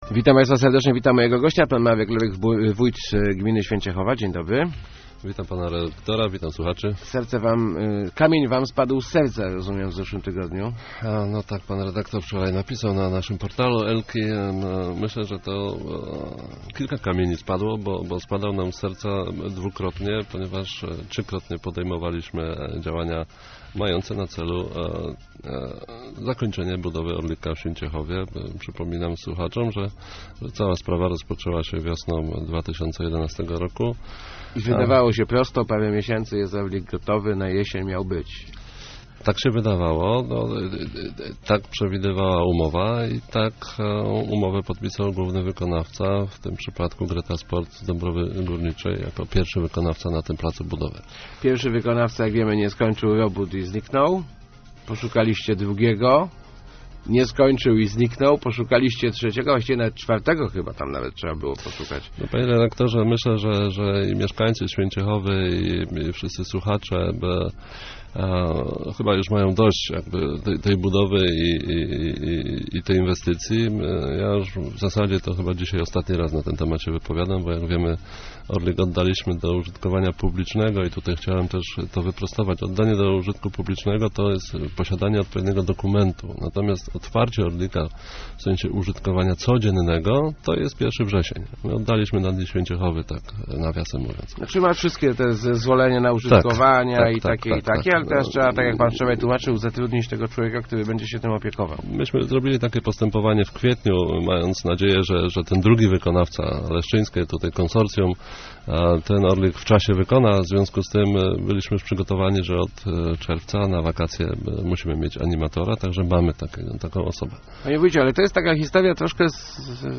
mlorych2808.jpg-Ustawa o zamówieniach publicznych powinna być poprawiona - mówił w Rozmowach Elki wójt Święciechowy Marek Lorych, komentując problemy gminy z dokończeniem budowy "Orlika". Jak przyznał firma, która pierwotnie wygrała przetarg chwaliła się doskonałymi referencjami.